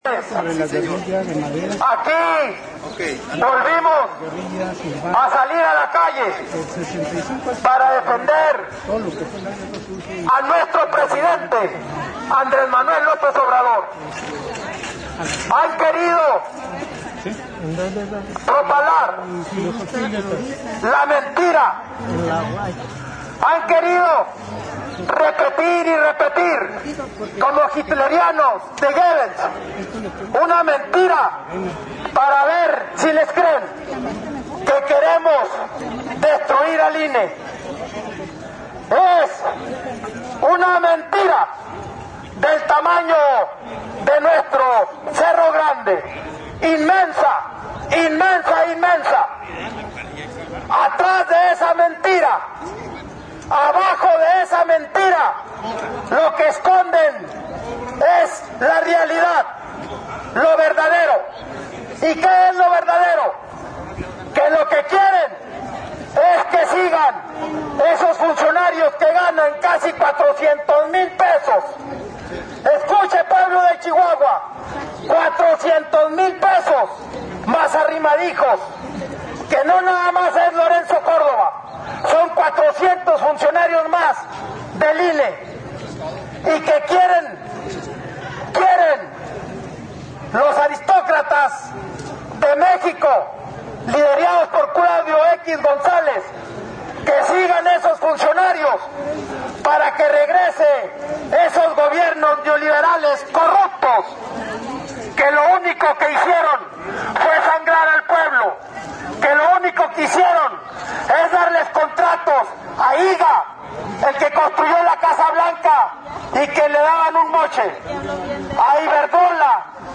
Chihuahua Chih.- El diputado David Oscar Castrejón Rivas, de morena, se manifestó junto con un grupo de ciudadanos frente al Congreso del Estado, en contra de los altos sueldos de funcionarios electorales nacionales y estatales, y criticó que la gobernadora de Chihuahua, el secretario de gobierno y el fiscal del estado también gozan de esos sueldos, que por mucho están por encima del Presidente de la República, violentando el artículo 127 de la Constitución Mexicana.